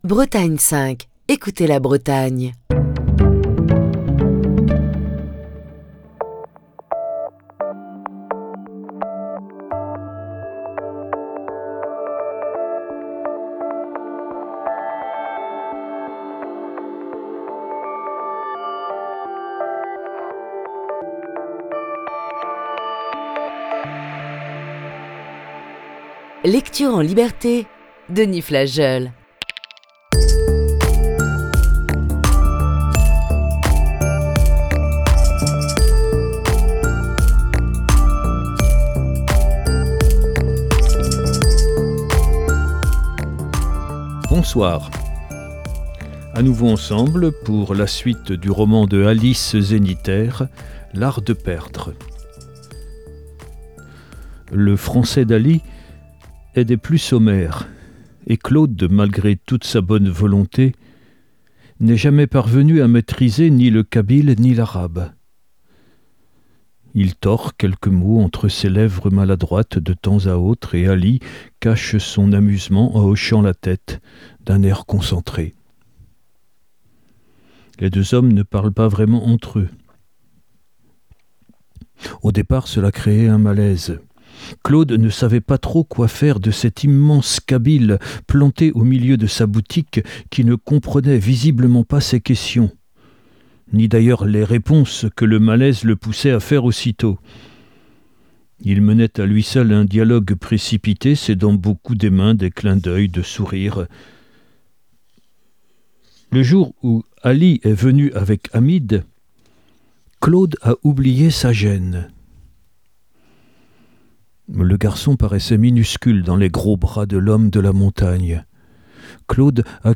Émission du 3 février 2022.